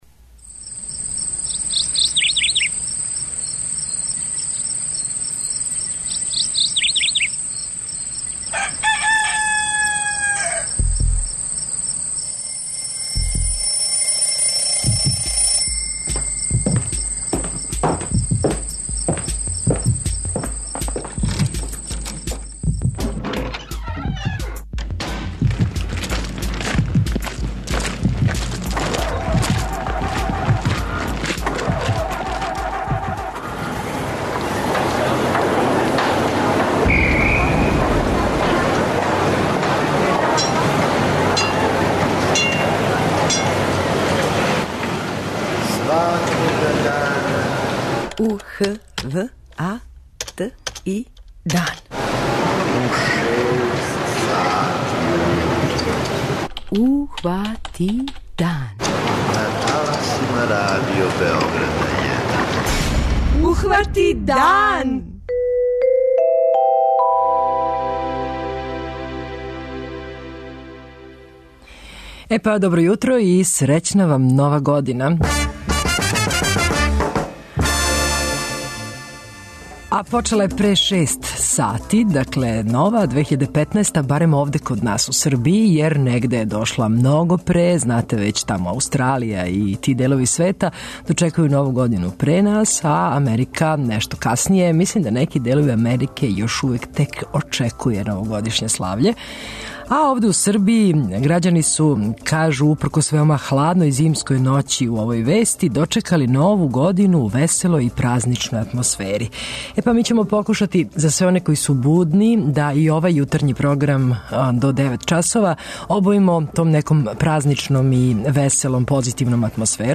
Први дан нове године хватамо уз музику и сервсине информације.